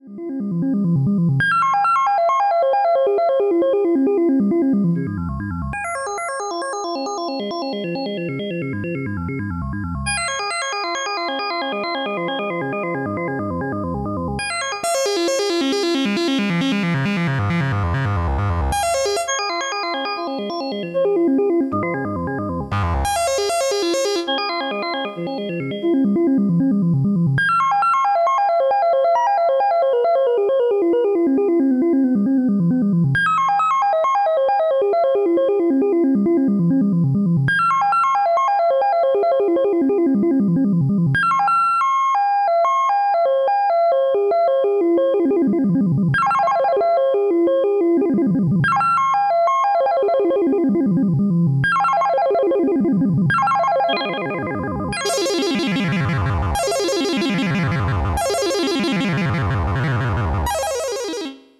Sound quality is excellent using a 12-bit DAC, 32kHz sample rate and 32-bit precision DSP computations.
More presets in this 4-part sequernce